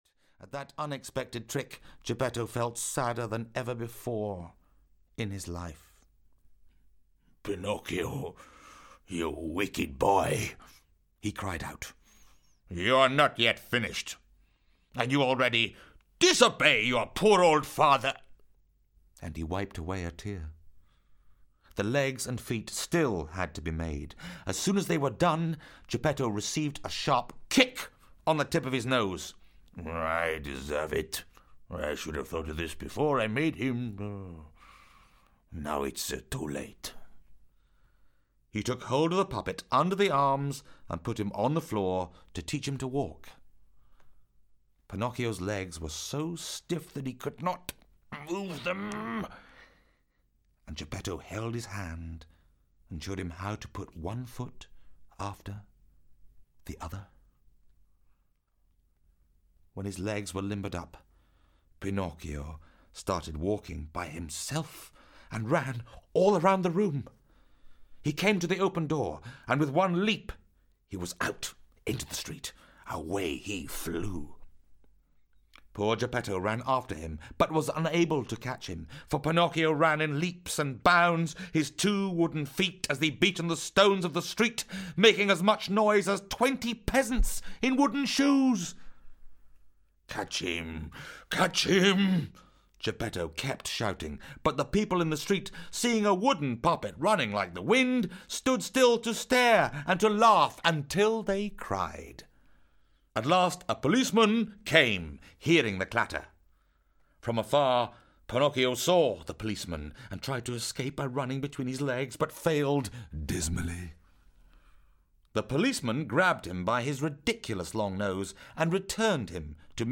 Pinocchio - Carlo Collodi - Hörbuch